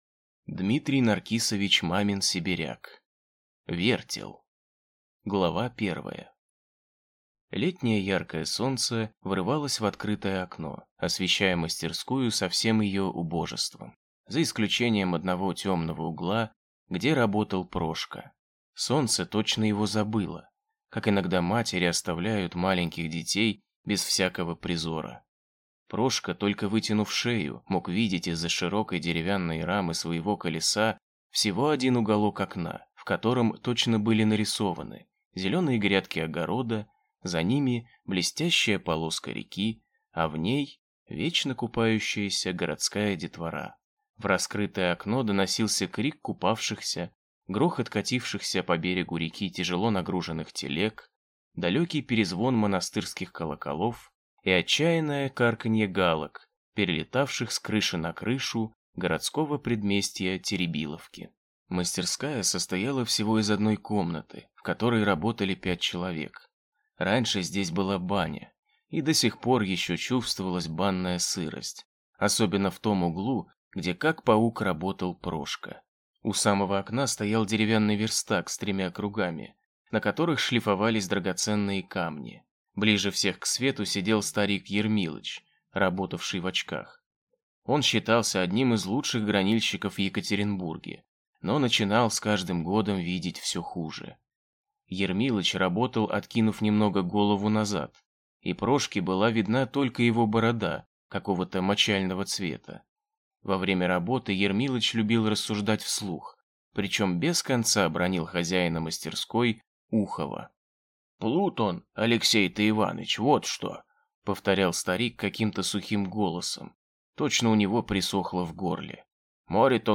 Аудиокнига Вертел | Библиотека аудиокниг
Прослушать и бесплатно скачать фрагмент аудиокниги